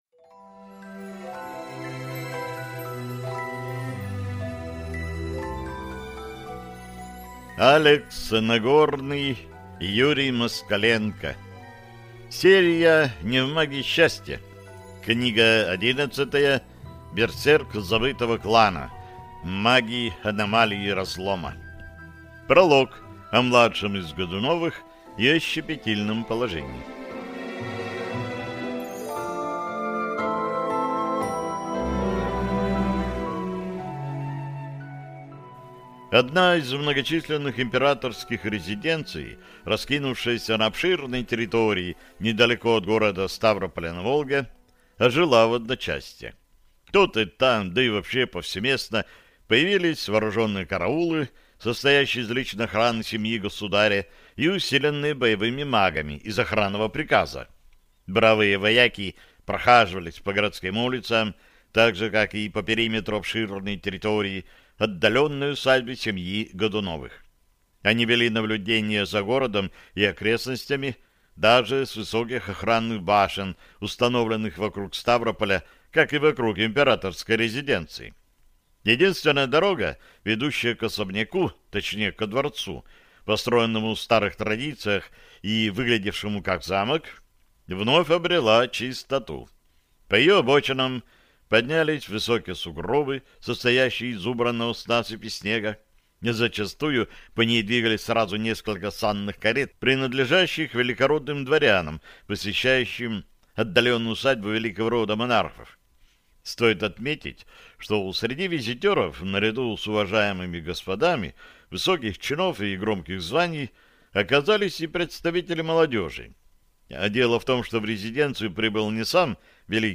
Аудиокнига Берсерк забытого клана. Маги Аномалии Разлома | Библиотека аудиокниг